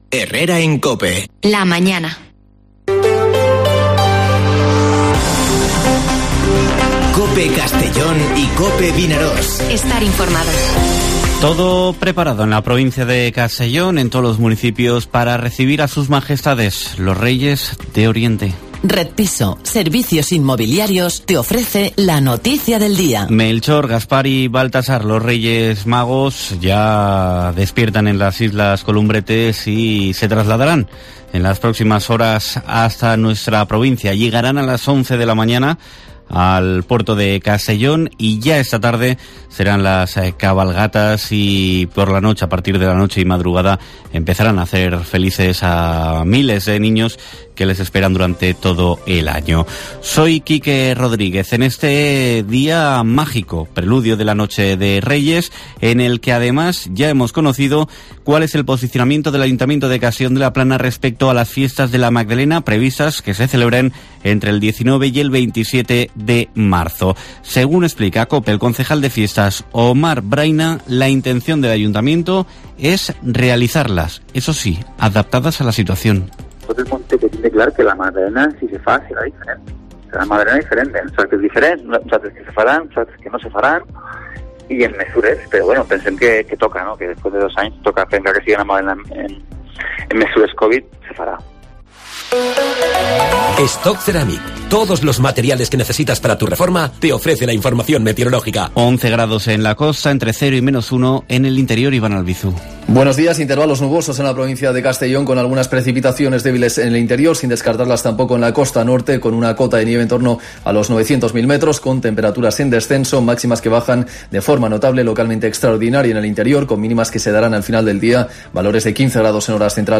Informativo Herrera en COPE en la provincia de Castellón (05/01/2022)